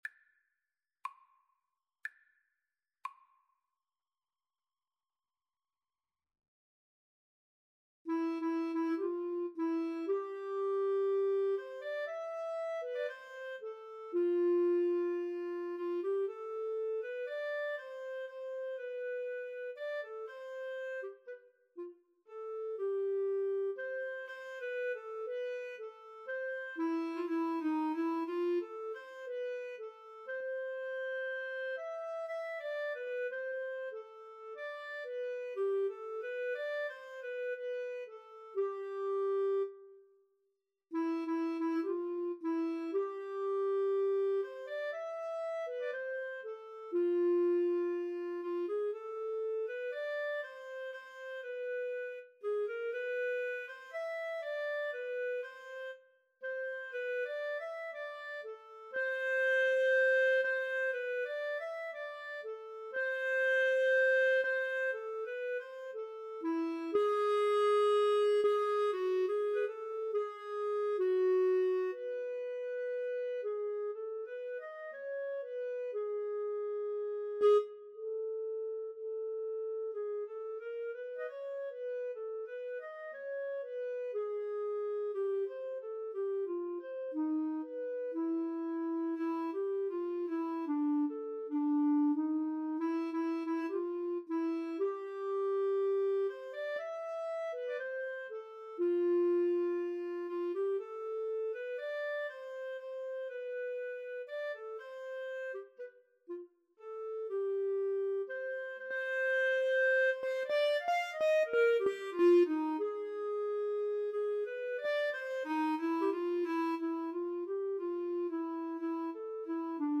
Free Sheet music for Clarinet-Cello Duet
2/4 (View more 2/4 Music)
C major (Sounding Pitch) D major (Clarinet in Bb) (View more C major Music for Clarinet-Cello Duet )
Classical (View more Classical Clarinet-Cello Duet Music)